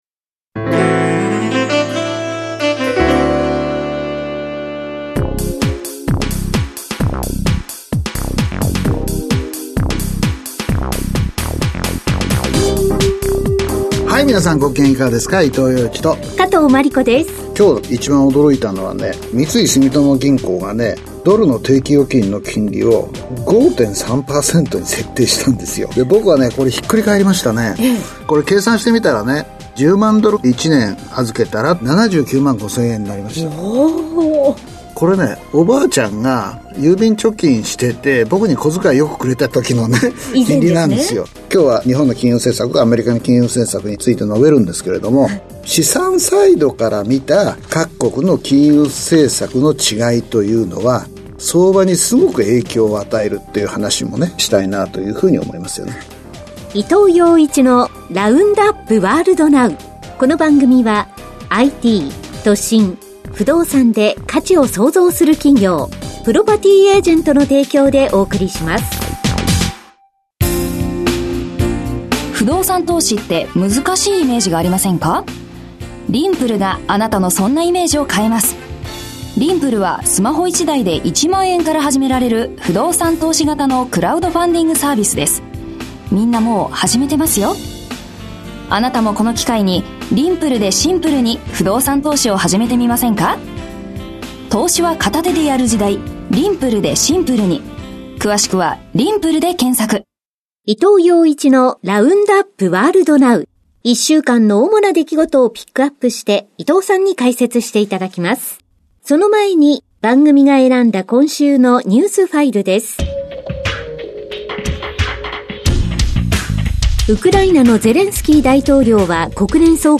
… continue reading 460 つのエピソード # ニューストーク # ニュース # ビジネスニュース # NIKKEI RADIO BROADCASTING CORPORATION